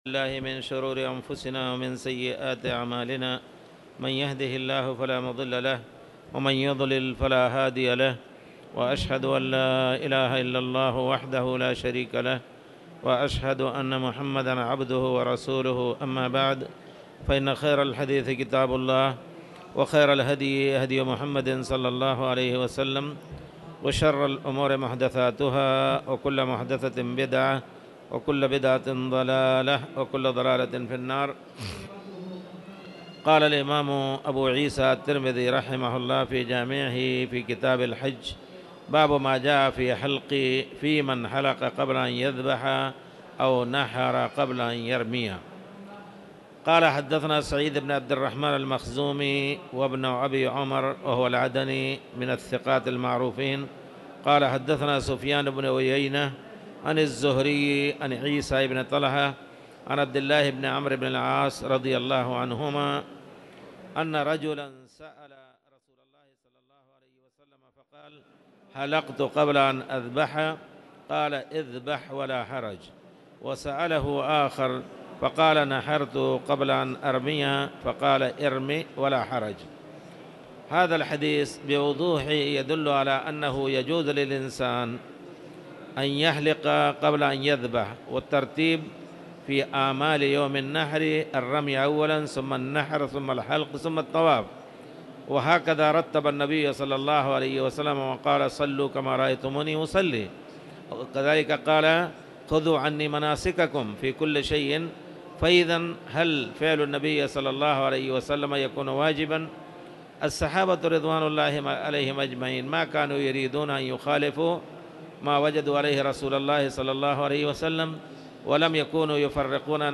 تاريخ النشر ٦ شعبان ١٤٣٧ المكان: المسجد الحرام الشيخ